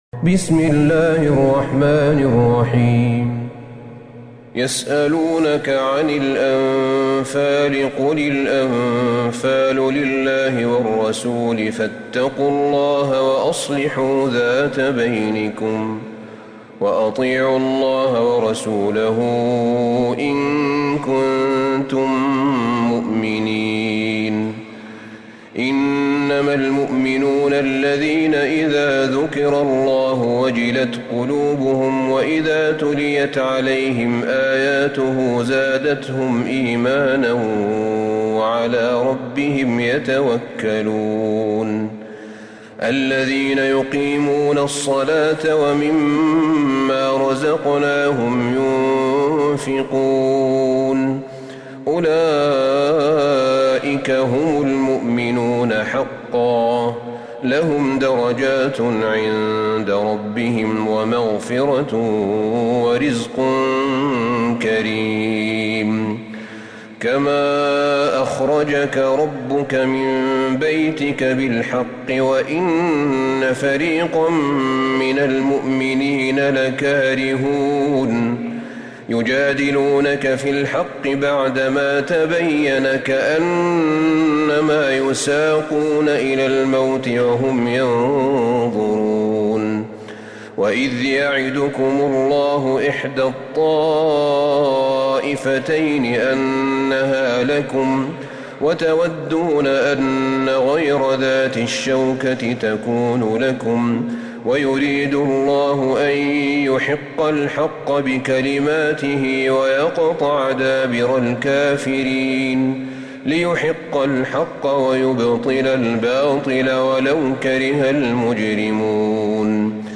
سورة الأنفال Surat Al-Anfal > مصحف الشيخ أحمد بن طالب بن حميد من الحرم النبوي > المصحف - تلاوات الحرمين